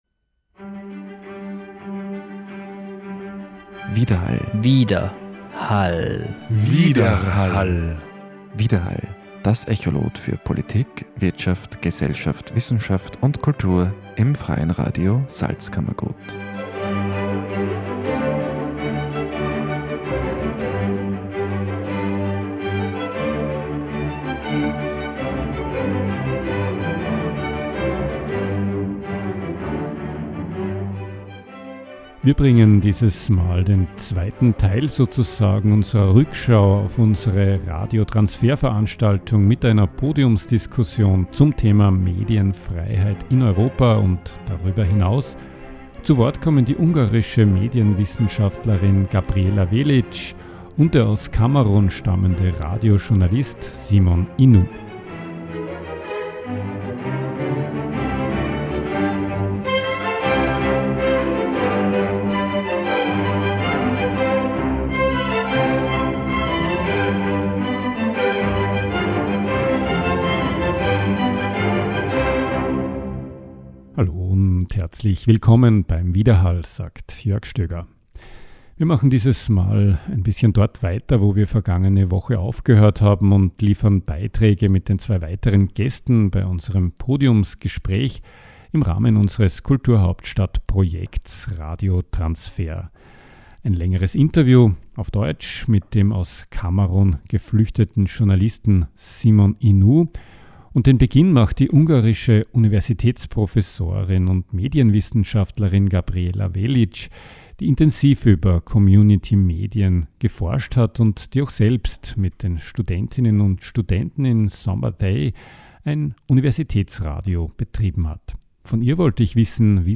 Im Interview geht sie genauer auf die Situation der Communi